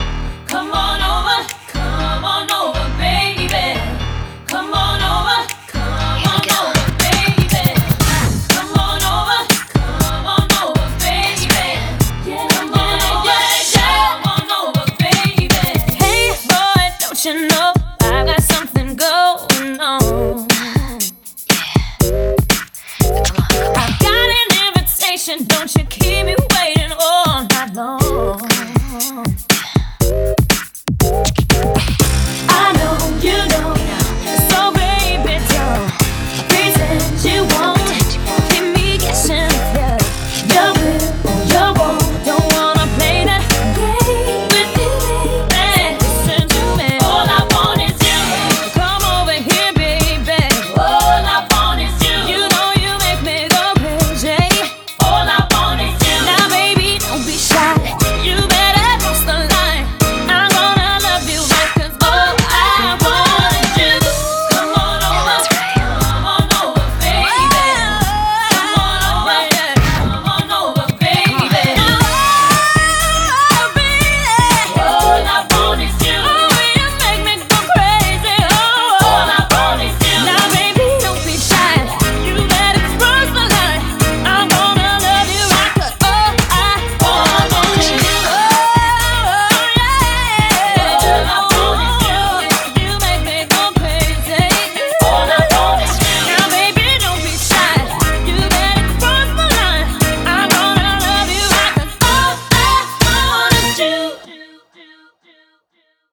BPM120
Sorry about the audio quality.